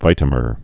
(vītə-mər)